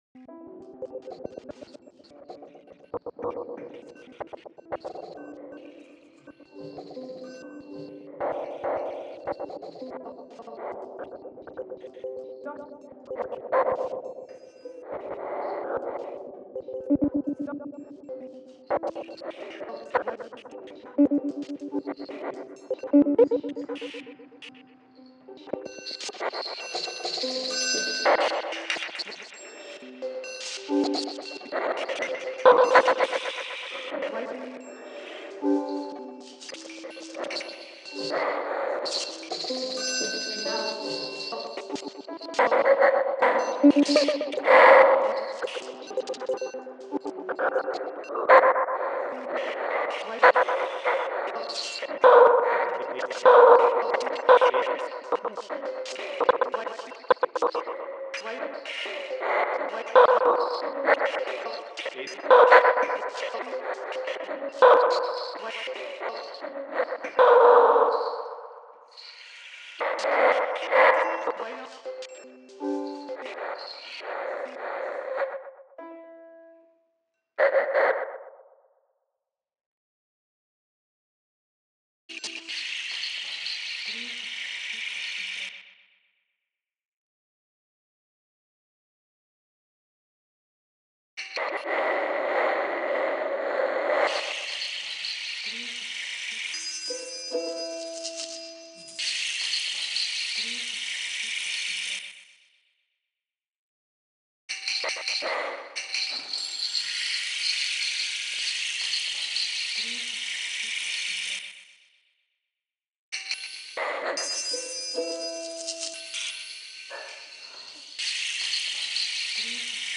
accessible youth and family art workshop
participants recorded improvised performances with instruments and household objects in Jack Straw’s recording studio.
creating a three dimensional soundscape.
Onix-Y-Marmol-Workshop-VR-Jam.mp3